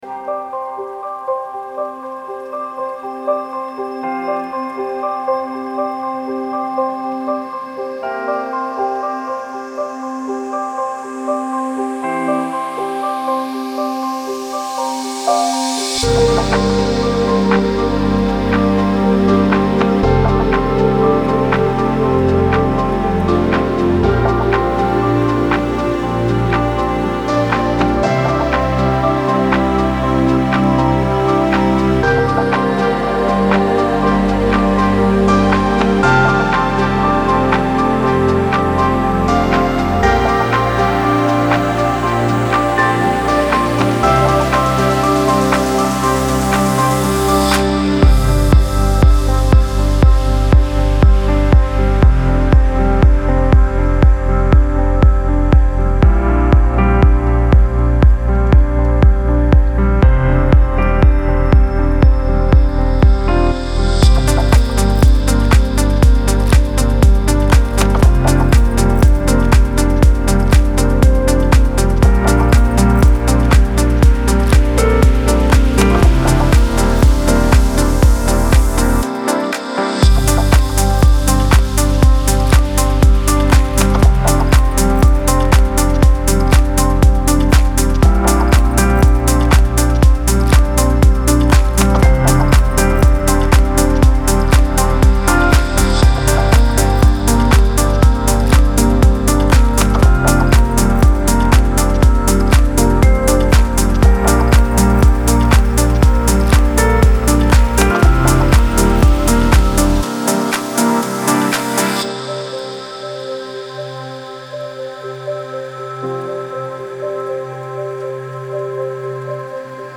چیل‌اوت ریتمیک آرام موسیقی بی کلام